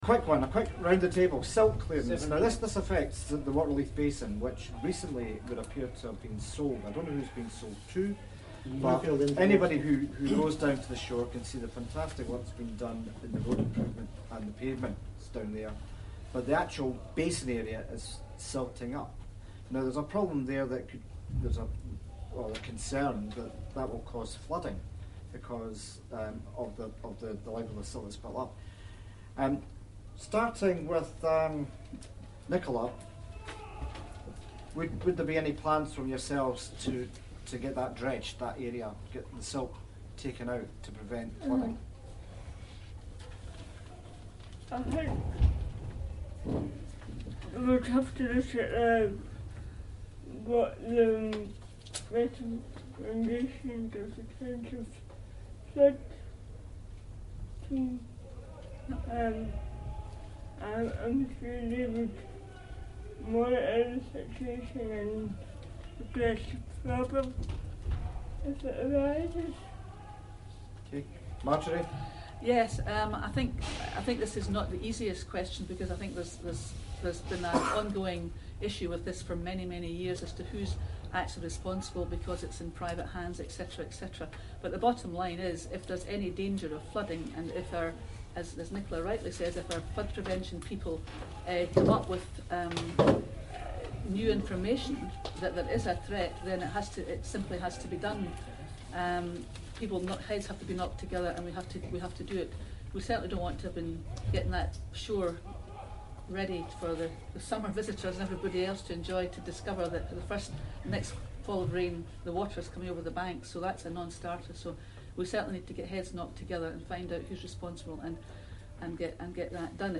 Leith Ward Hustings - Q6 - Flooding at The Shore